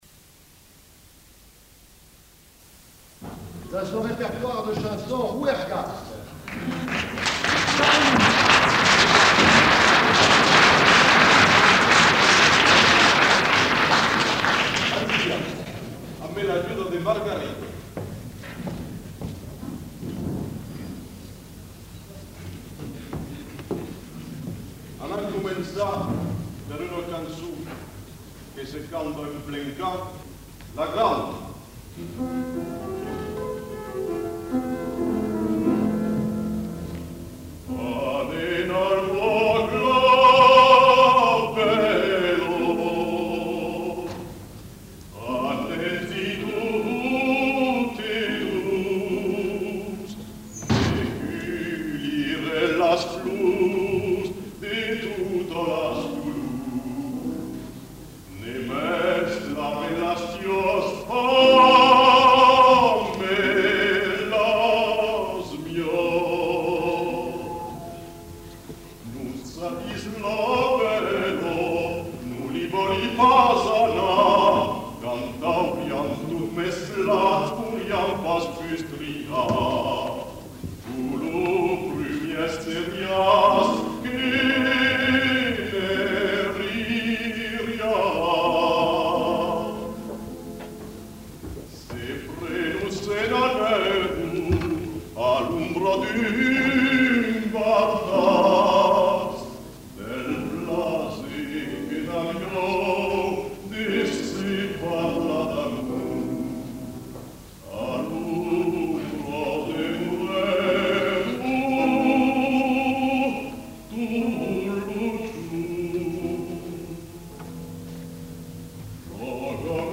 Lieu : Villefranche-de-Rouergue
Genre : chanson-musique
Type de voix : voix d'homme
Production du son : chanté
Instrument de musique : piano
Notes consultables : La qualité technique étant mauvaise, les paroles difficiles à comprendre.